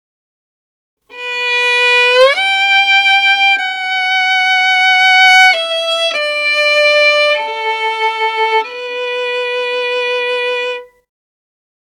Saddest_Violin_1
cinema famous film funny hearts-and-flowers motif movie sad sound effect free sound royalty free Movies & TV